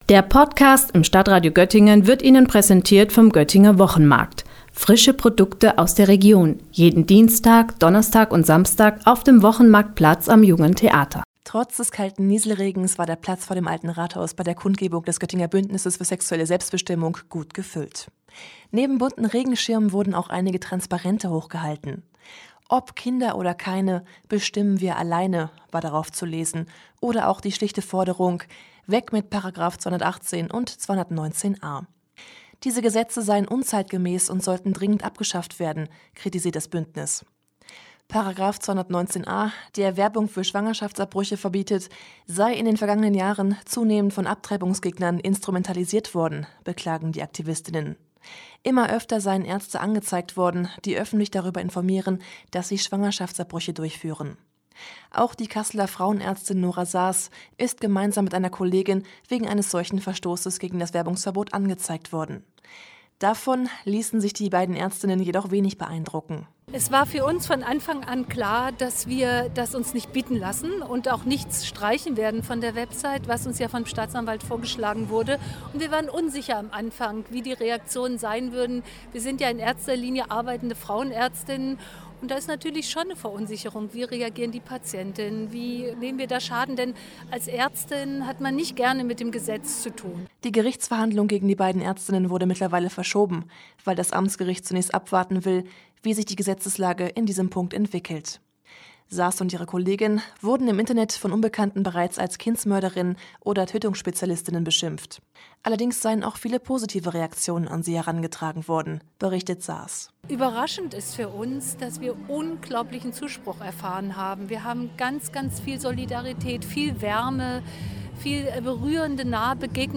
Bei einem bundesweiten Aktionstag haben am Samstag mehrere tausend Menschen in rund 30 deutschen Städten die Abschaffung des sogenannten Werbeverbots für Abtreibungen gefordert. Auch in Göttingen hatte das Bündnis für sexuelle Selbstbestimmung zu einer Kundgebung eingeladen.